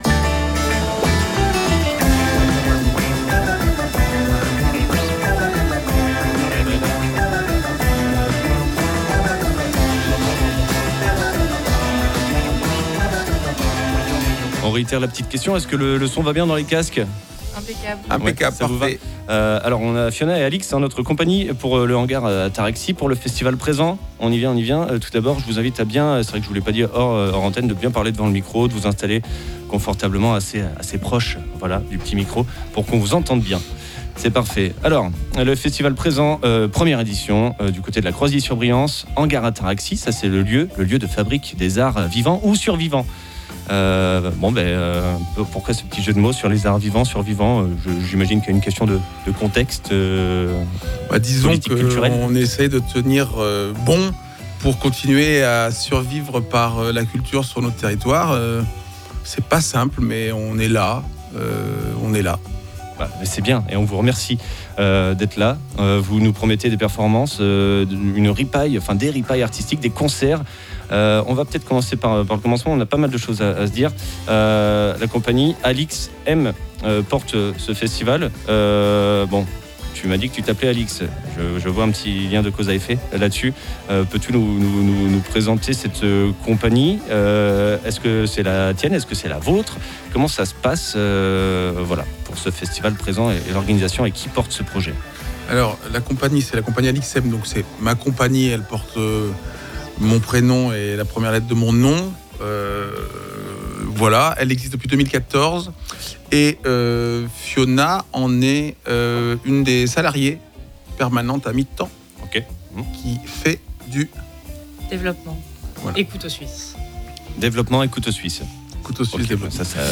ITW-HANGAR-ATARAXIE_FEST_PRESENT1-1.mp3